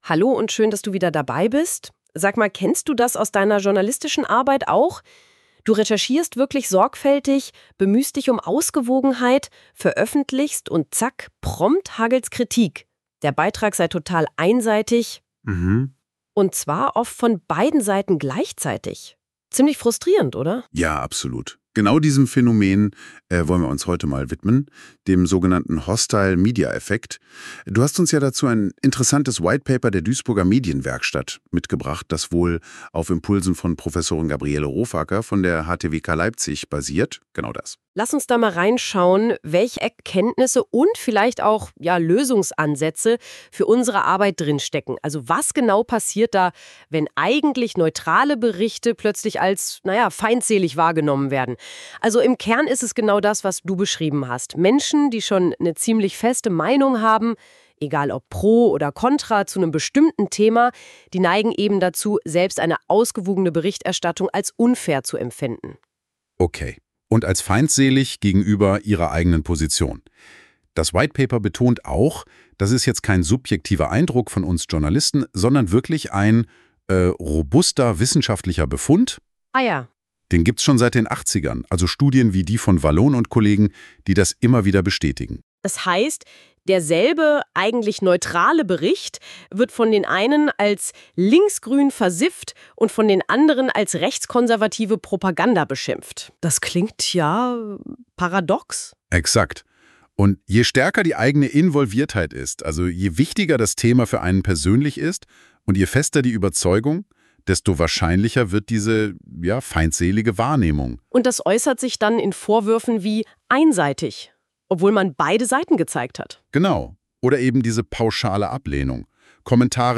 Unser KI-Podcast beleuchtet, wie Redaktionen trotz Verzerrungen Vertrauen schaffen können.